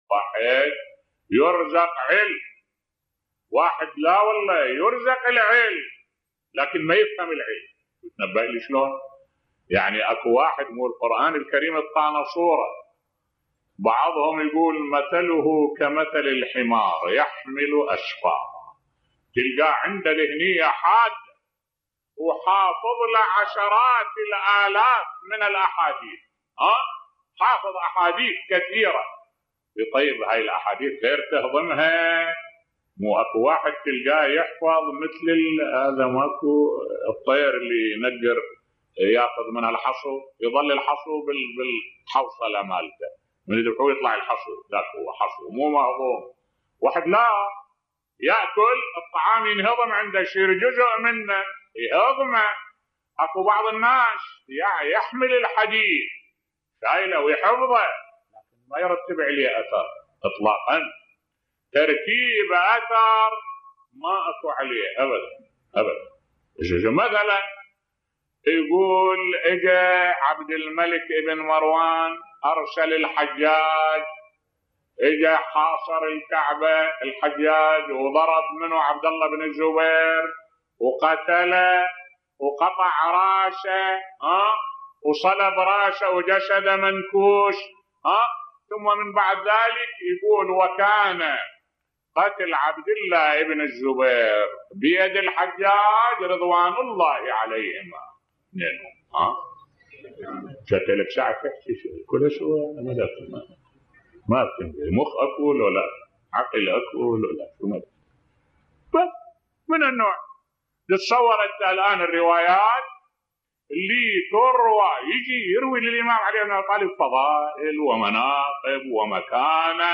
ملف صوتی بعض من يدعي العلم تطبق عليه الآية الكريمة كمثلِ الحمار يحمل أسفارا بصوت الشيخ الدكتور أحمد الوائلي